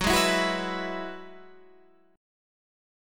Listen to F#7sus2sus4 strummed